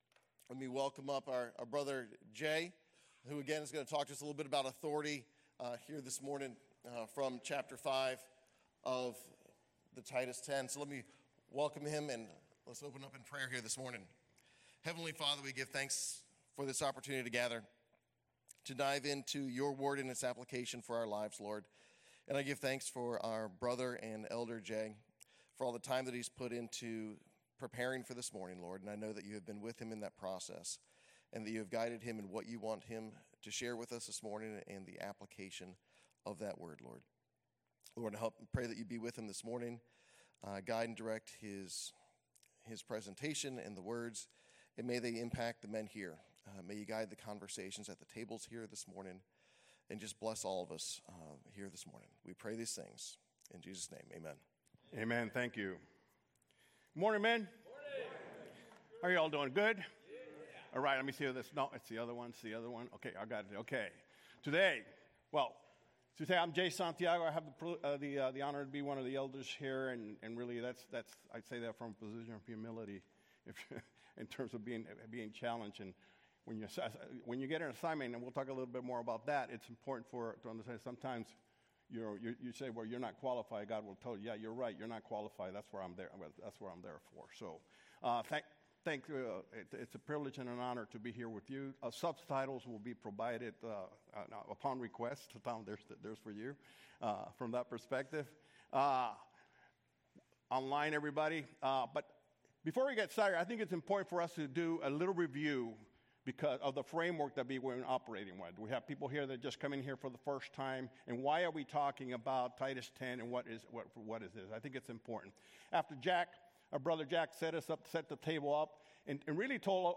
Basecamp Bible Study Titus Ten - Authority Oct 07 2025 | 00:24:36 1x 00:00 / 00:24:36 Subscribe Share Apple Podcasts Spotify Amazon Music Overcast RSS Feed Share Link Embed